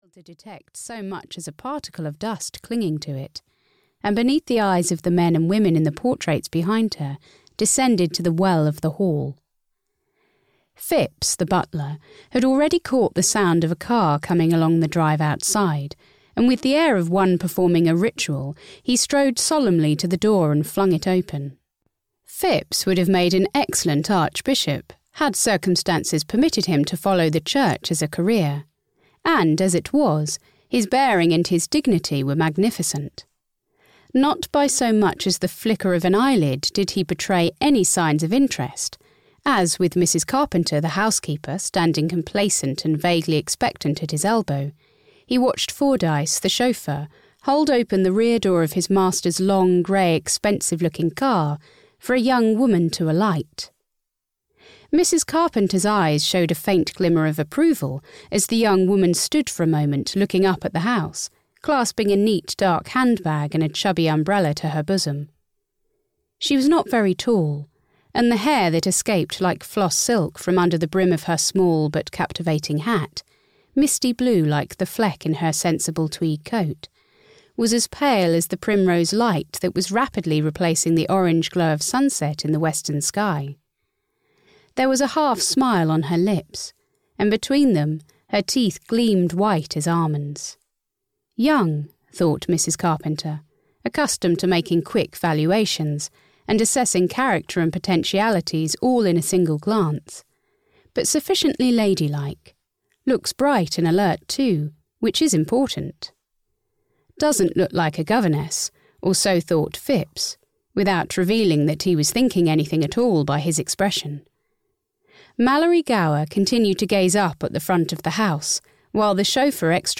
The Black Benedicts (EN) audiokniha